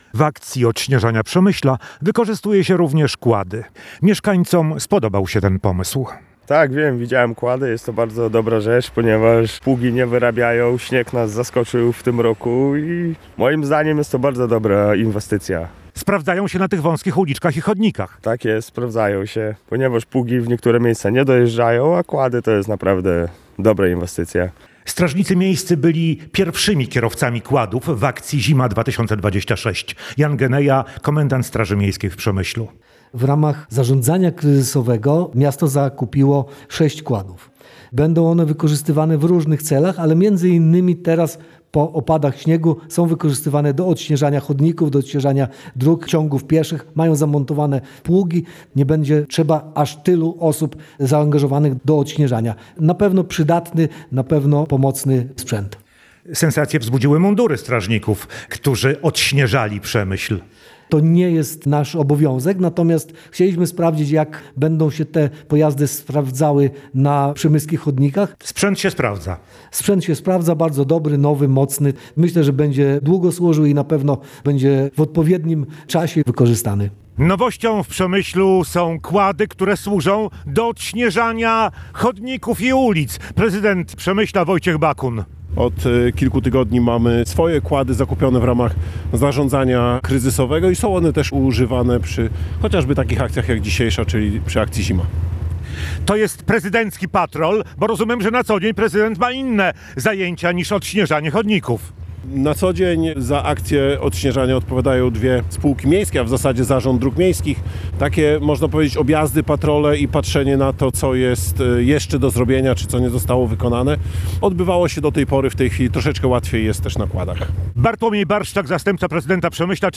To dobre pojazdy, bardzo mobilne, potrafiące odśnieżać długie odcinki chodników i wąskich uliczek – zachwala nowy sprzęt prezydent Przemyśla Wojciech Bakun.
Relacja